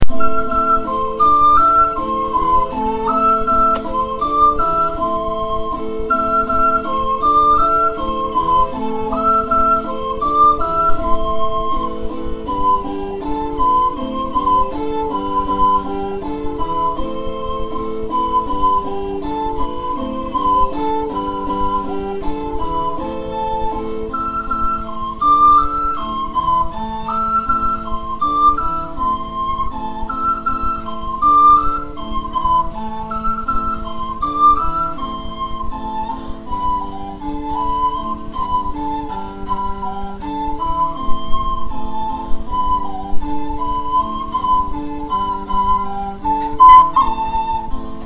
Dragon Ocarina Clay Flutes